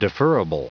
Prononciation du mot deferrable en anglais (fichier audio)
Prononciation du mot : deferrable